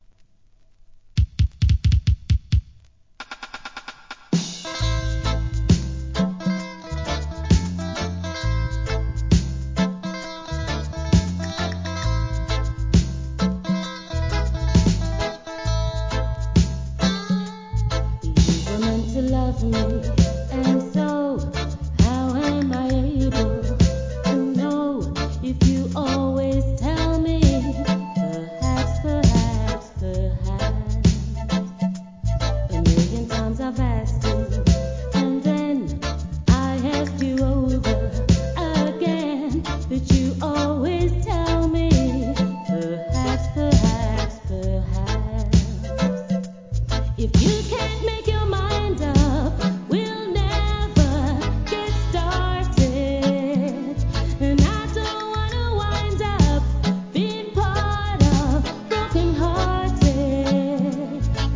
REGGAE
怒渋なROOTSトラックでフィメール・シンガー!